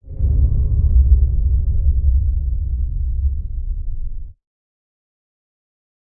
标签： 处理
声道立体声